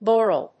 /ˈbɔrʌl(米国英語), ˈbɔ:rʌl(英国英語)/